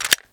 Pistol_MagIn.wav